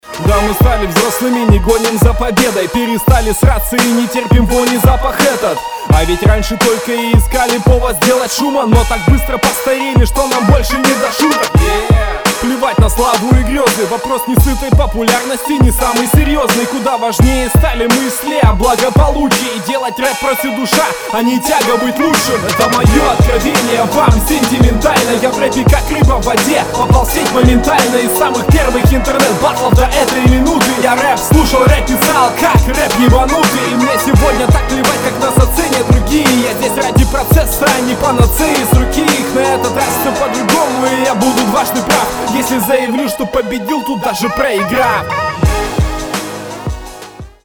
Чуть-чуть уверенности и постановки стиля не хватает, но задатки имеются вполне и местами вполне неплохо звучишь.
Средний тречок, но на сентиментальность пробило